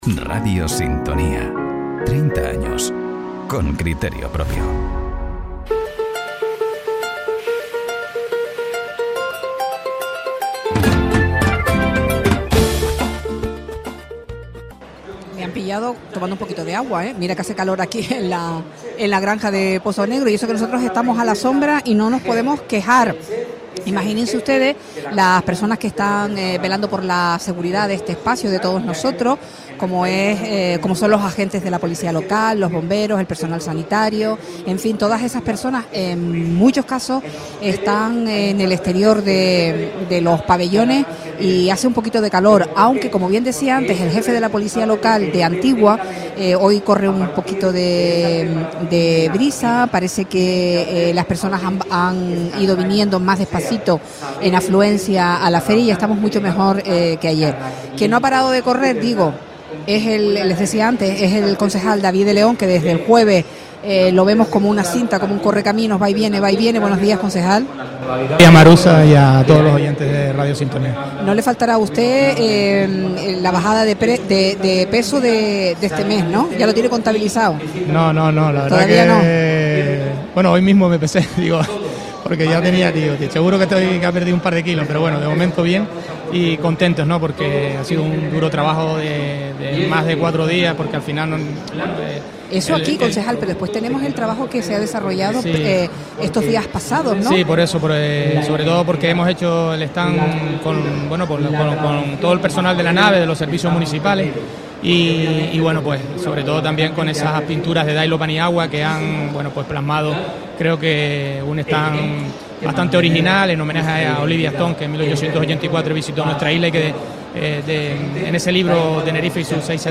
El alcalde y el concejal del sector primario analizan el paso del municipio de Puerto del Rosario en la feria con los micrófonos de Radio Sintonía Deja un comentario
Entrevistas